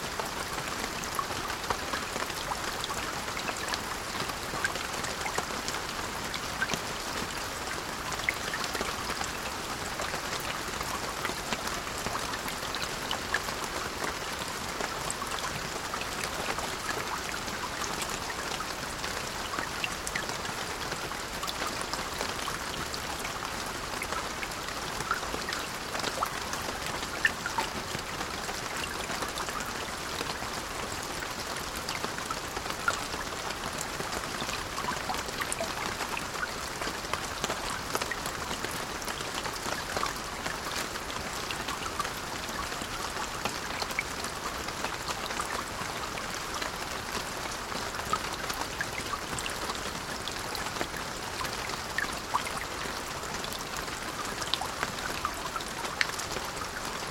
enviro_rain_1.wav